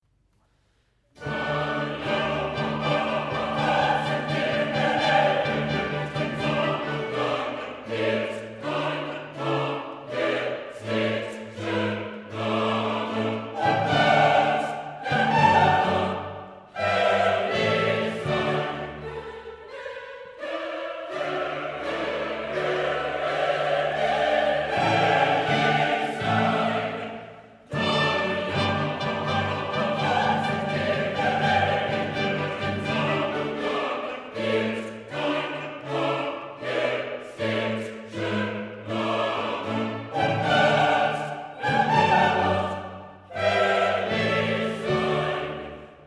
Dame Jane Glover, conductor
Music of the Baroque Chorus and Orchestra
while blasphemers howl in descending chromatic lines.